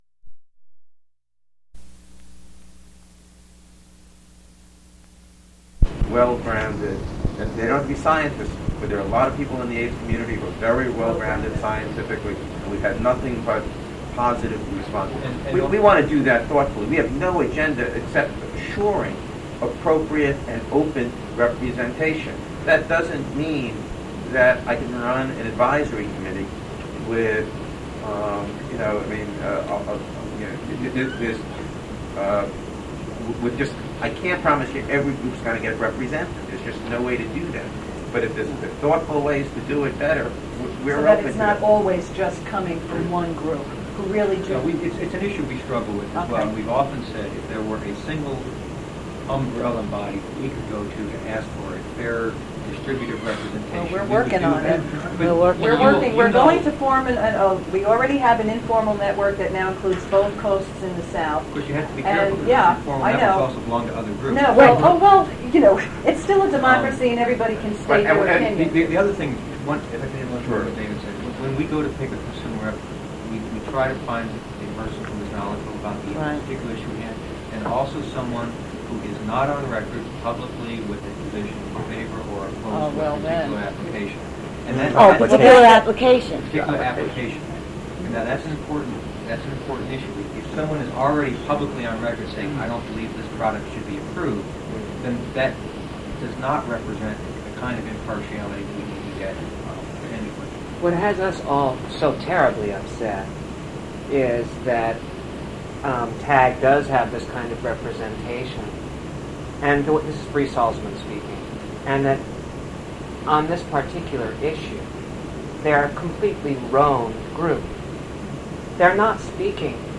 Meeting between ACT UP and other HIV/AIDS activists and employees of the Federal Drug Administration regarding recent FDA activities in accelerated approval and expanded access to drugs.
Also included in the meeting are researchers who studied HIV/AIDS in clinical trials, as well as physicians at various clinics. The purpose for the meeting was to discuss recent activities of the FDA regarding accelerated approval and expanded access to drugs. Also discussed are the influence of corporations and recent scandals.
Physical Format Audio cassette